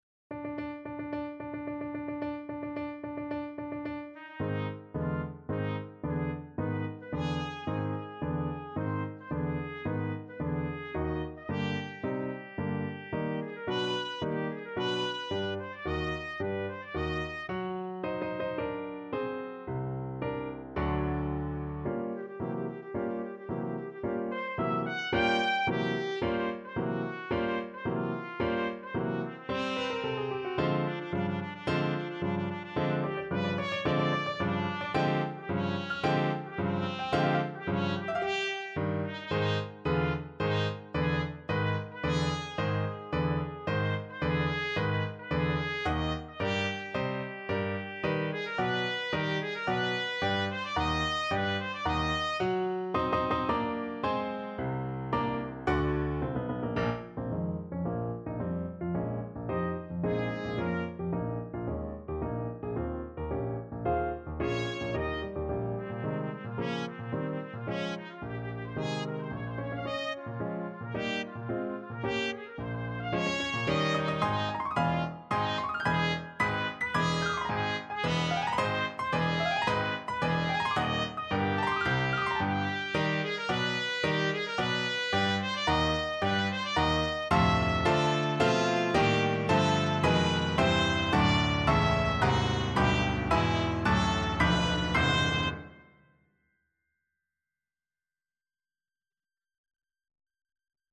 ~ = 110 Tempo di Marcia